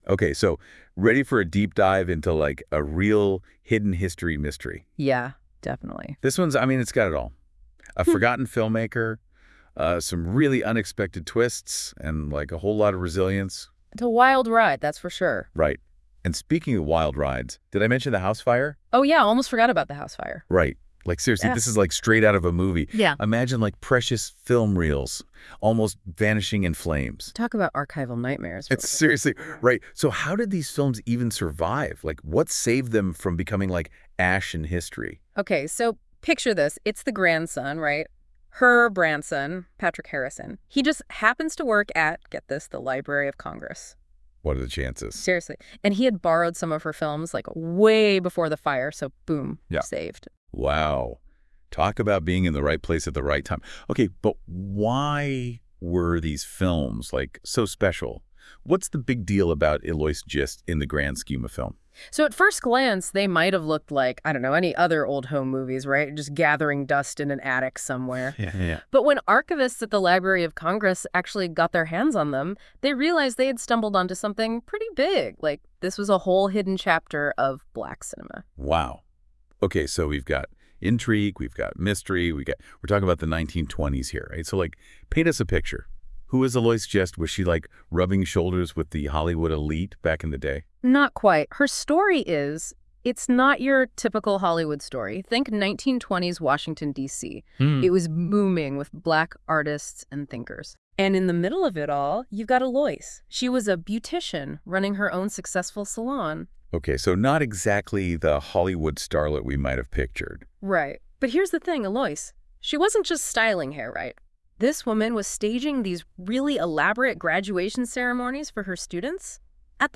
So once again I played around with A.I. and got something, not 100% right.